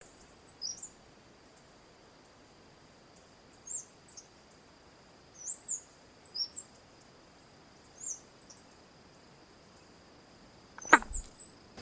Cerquero Cabeza Castaña (Atlapetes fulviceps)
Nombre en inglés: Fulvous-headed Brushfinch
Fase de la vida: Adulto
Localidad o área protegida: Parque Nacional Calilegua
Condición: Silvestre
Certeza: Fotografiada, Vocalización Grabada